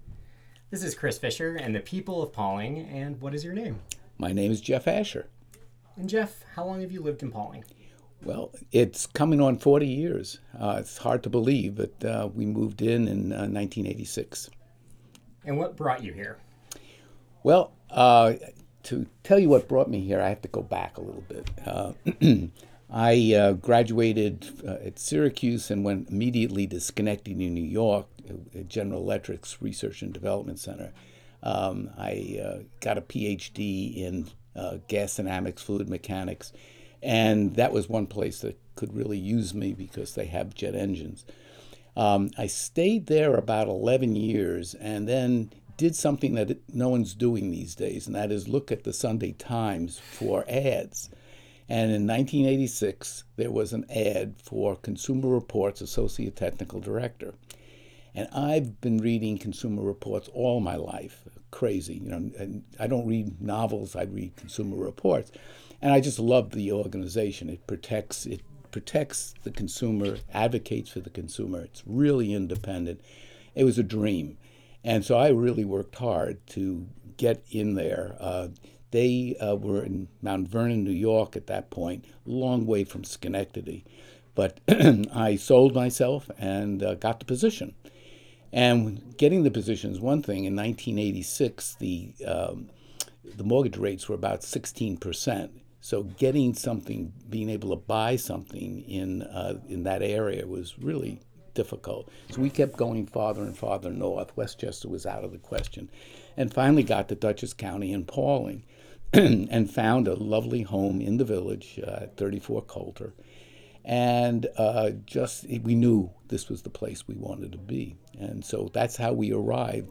The conversation was recorded as part of the People of Pawling Project. The project saught to create brief snapshots of Pawling that could be easily consumed and show the richness of the community during the time that the recordings were done.